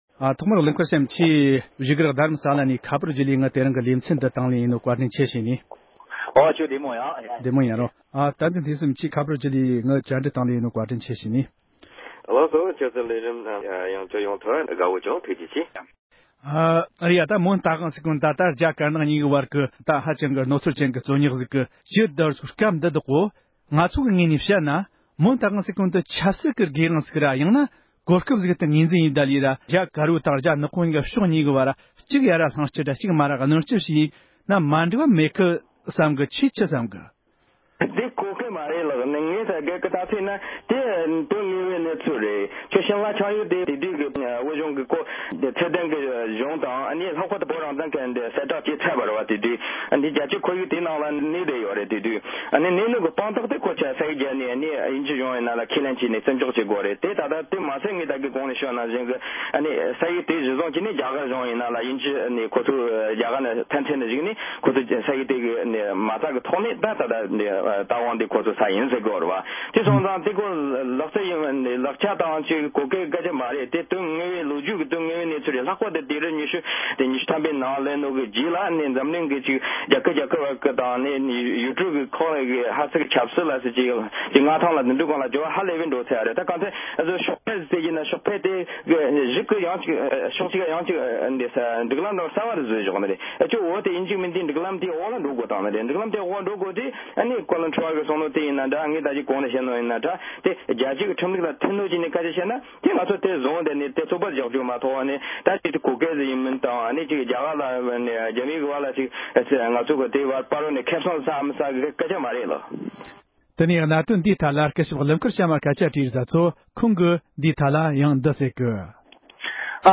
རྒྱ་གར་བྱང་ཤར་དུ་གནས་པའི་མོན་རྟ་དབང་ས་ཁུལ་ཐད་བགྲོ་གླེང༌།